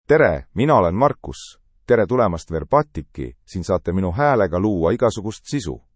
MarcusMale Estonian AI voice
Marcus is a male AI voice for Estonian (Estonia).
Voice sample
Listen to Marcus's male Estonian voice.
Marcus delivers clear pronunciation with authentic Estonia Estonian intonation, making your content sound professionally produced.